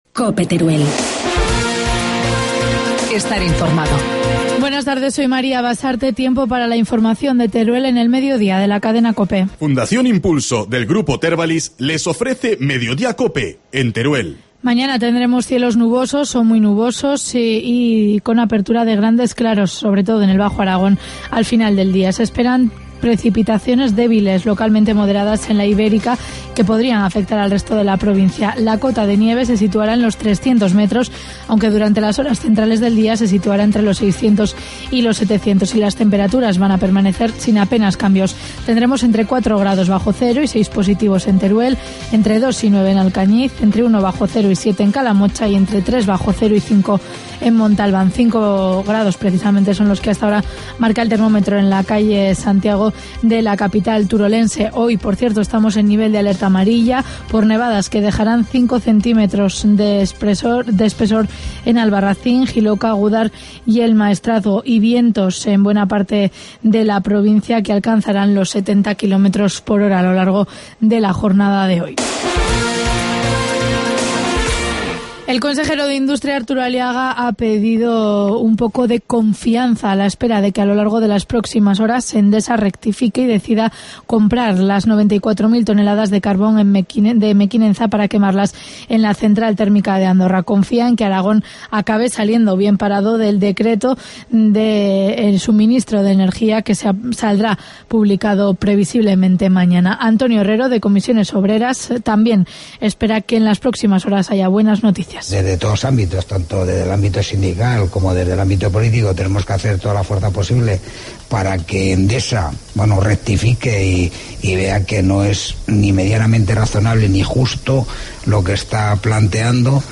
Informativo mediodía, jueves 7 de febrero